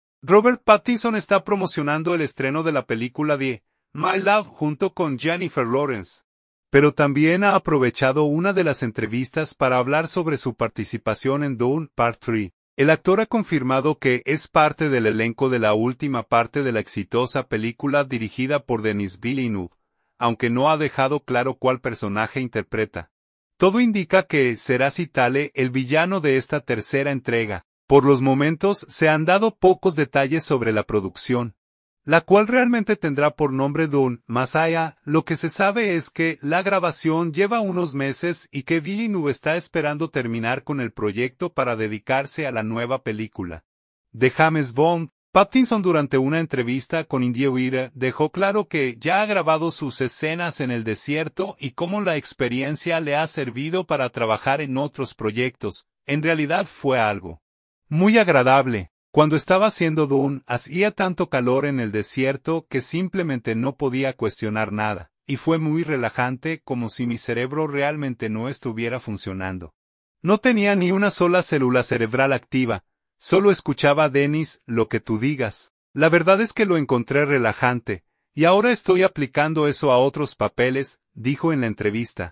Durante una reciente entrevista, Robert Pattinson confirmó que es parte del elenco de la nueva entrega de ‘Dune’ y habló sobre cómo fue grabar en el desierto